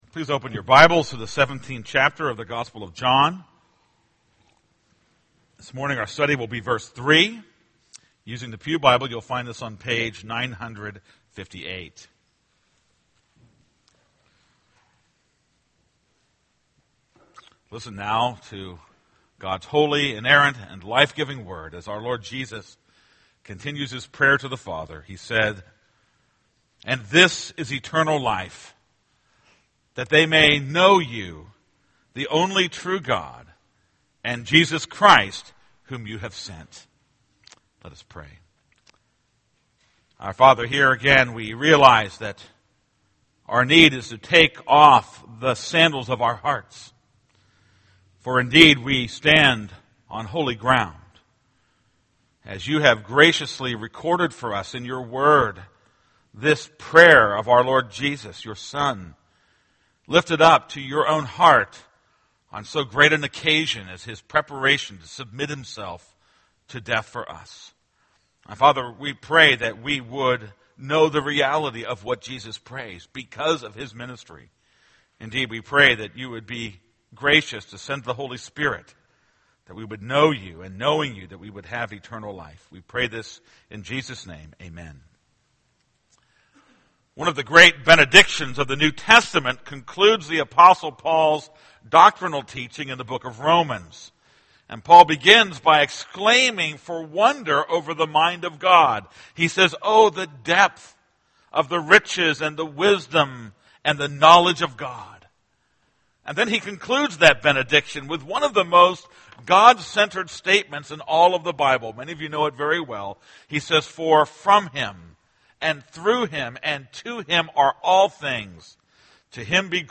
This is a sermon on John 17:3.